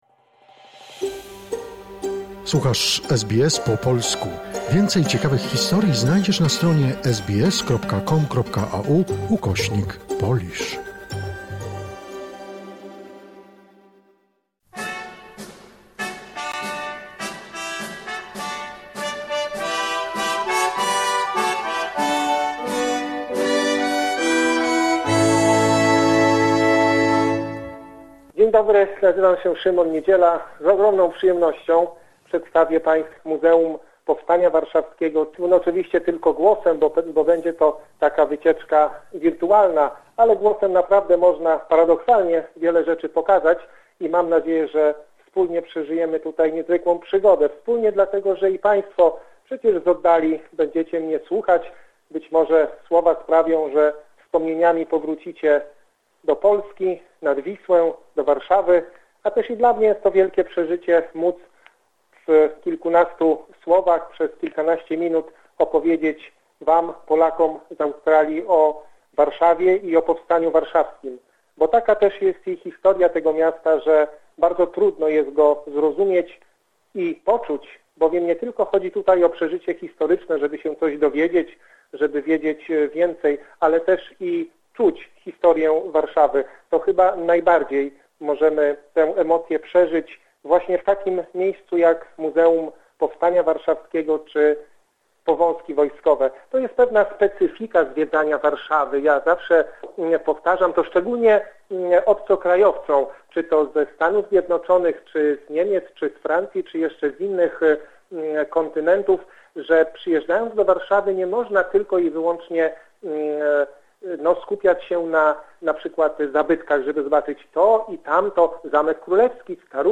Spacerkiem po Muzeum Powstania Warszawskiego - cz.1
Przewodnik tłumaczy dlaczego warszawiacy podjęli walkę w powstaniu, które wybuchło 1 sierpnia 1944 roku i trwało do pazdziernika 1944 roku.